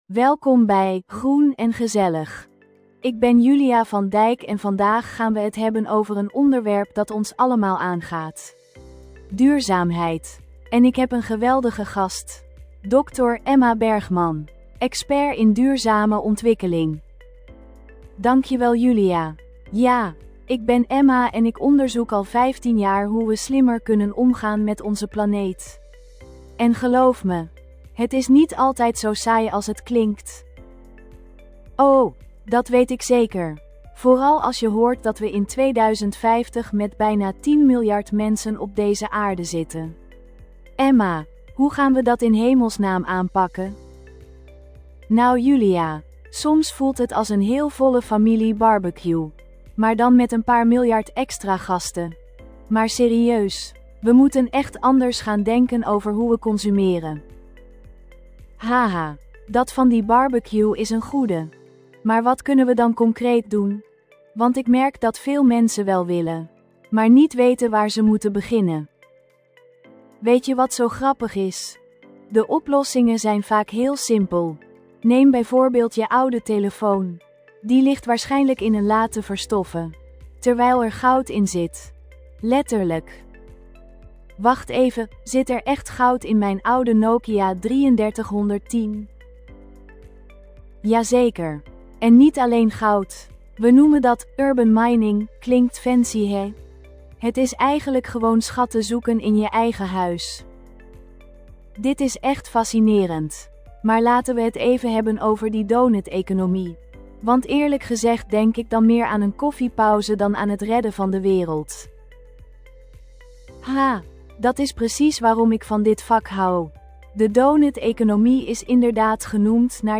Podcast gegenereerd van tekst content (2992 karakters)